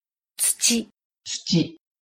prononciation-de-tsuchi.mp3